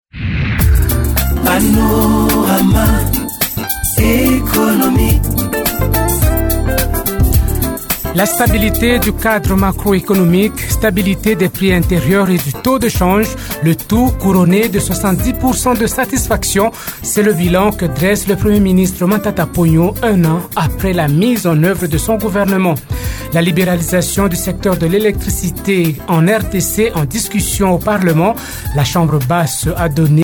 Le président de la commission économique et financière de l’Assemblée nationale, Jean-Luc Mutokambali est l’invité du panorama économique cette semaine.